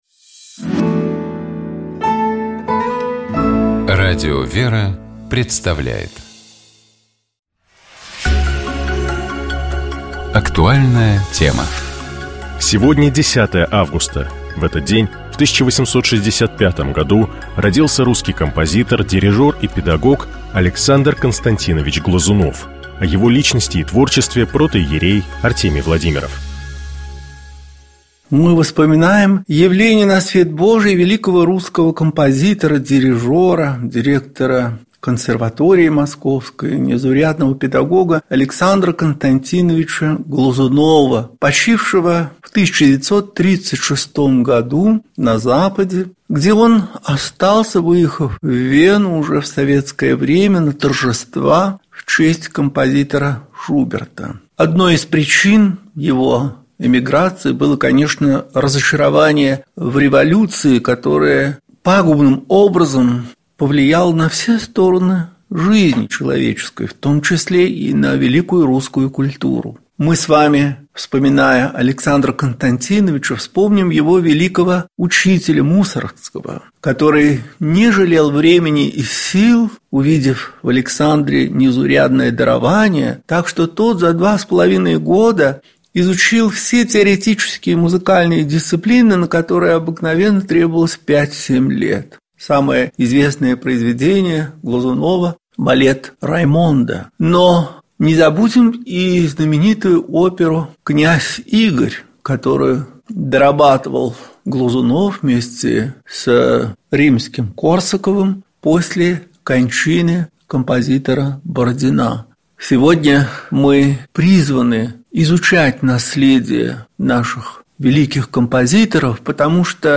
Общая теплая палитра программы «Еженедельный журнал» складывается из различных рубрик: эксперты комментируют яркие события, священники объясняют евангельские фрагменты, специалисты дают полезные советы, представители фондов рассказывают о своих подопечных, которым требуется поддержка.